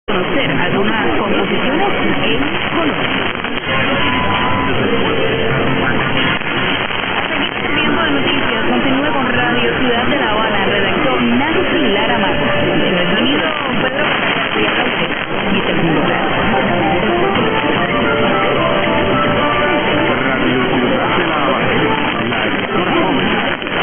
820 | CUBA | R. Ciudad de la Habana, JAN 15 0200 - Radio Ciudad de la Habana ID's; over Reloj and Progreso (no trace of WNYC).